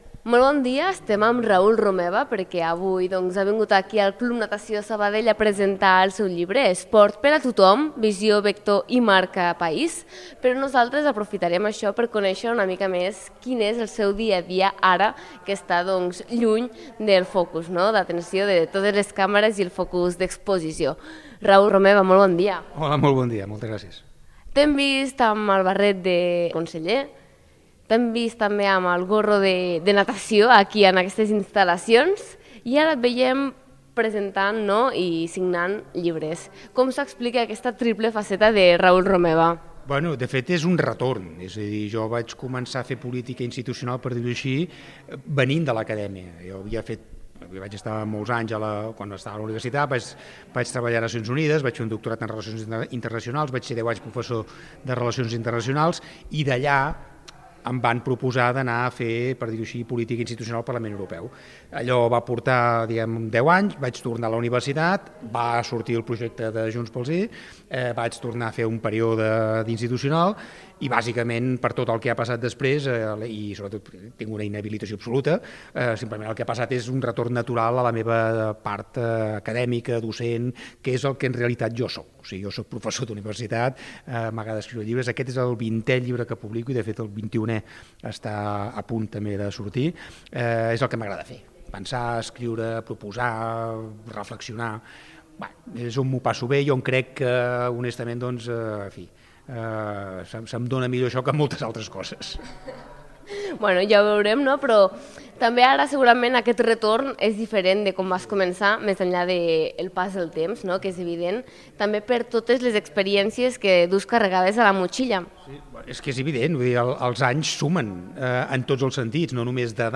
ENTREVISTA RAUL ROMEVA.mp3